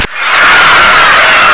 home *** CD-ROM | disk | FTP | other *** search / AMOS PD CD / amospdcd.iso / 526-550 / apd549 / crowd.abk ( .mp3 ) < prev next > AMOS Samples Bank | 1989-08-15 | 23KB | 1 channel | 16,000 sample rate | 1 second